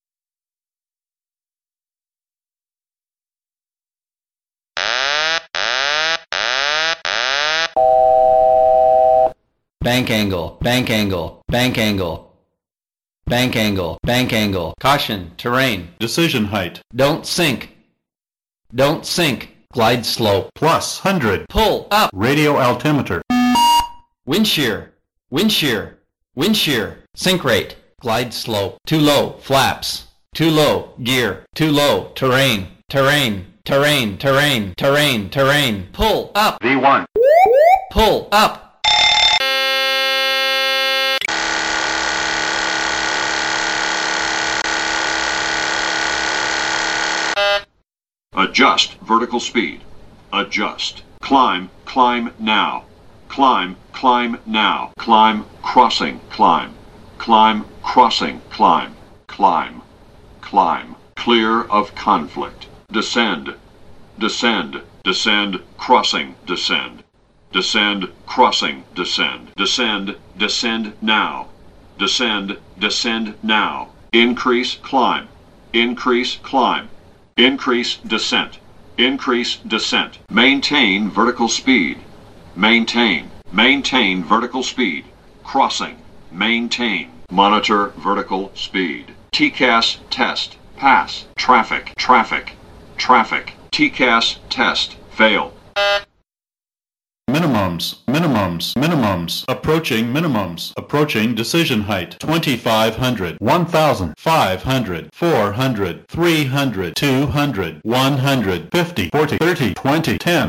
Alarms, T-CAS and GPWS of Boeing 737.wav